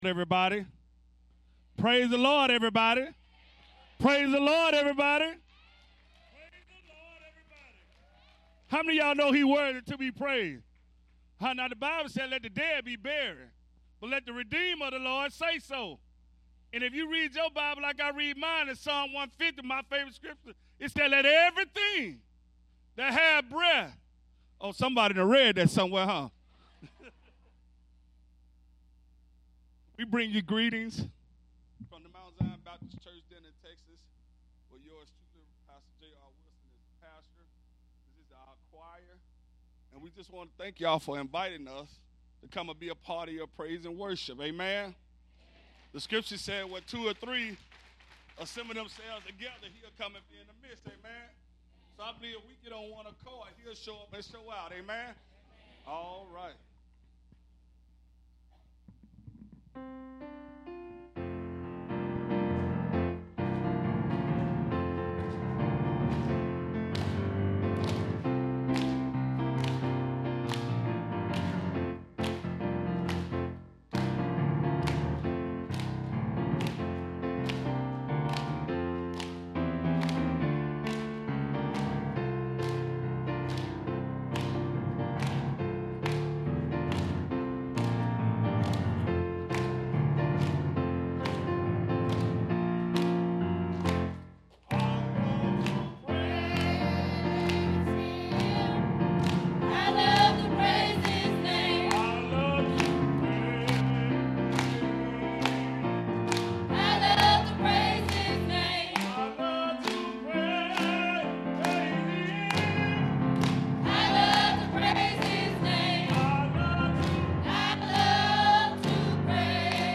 Bible Text: Colossians 3:2 | A night of worship where multiple churches came together to celebrate our Lord and Savior!